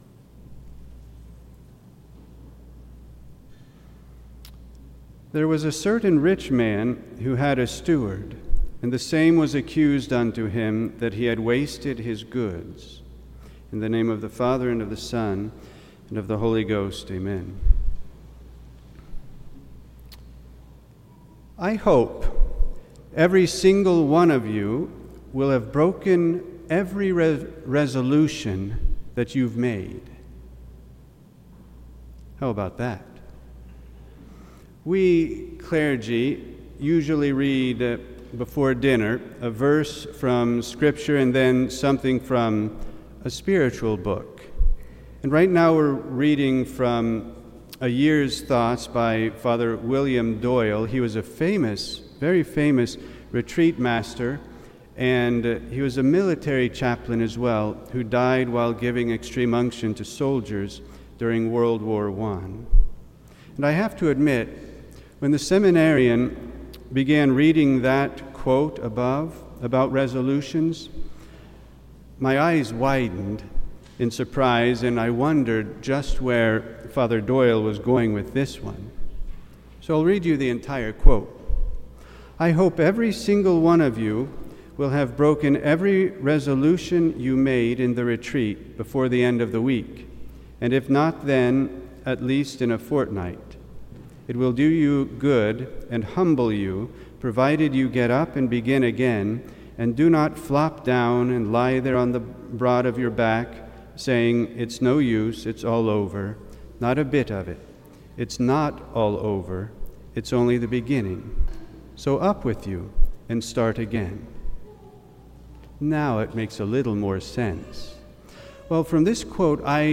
This entry was posted on Sunday, August 3rd, 2025 at 5:10 pm and is filed under Sermons.